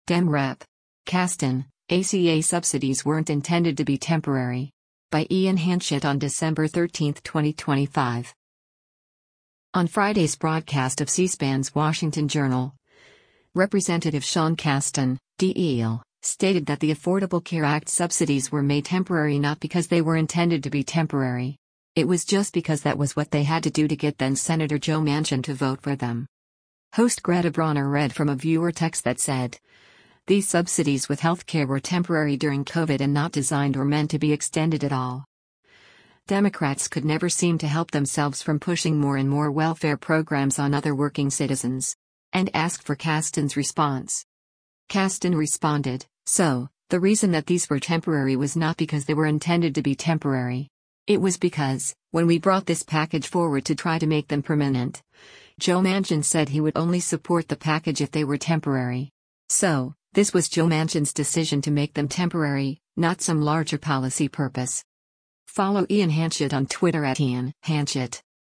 On Friday’s broadcast of C-SPAN’s “Washington Journal,” Rep. Sean Casten (D-IL) stated that the Affordable Care Act subsidies were made temporary “not because they were intended to be temporary.” It was just because that was what they had to do to get then-Sen. Joe Manchin to vote for them.